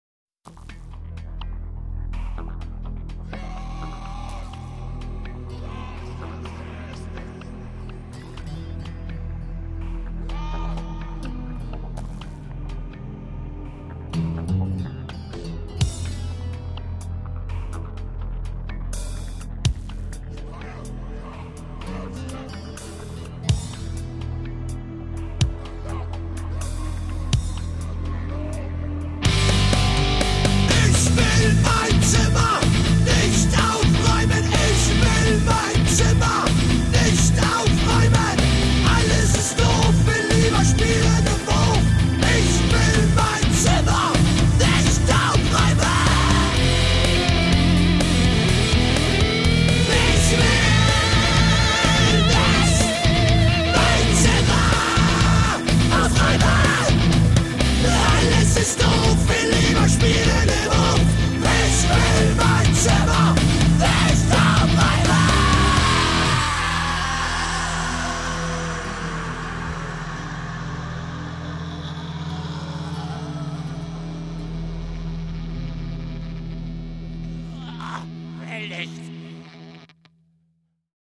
Distortion
distorted.mp3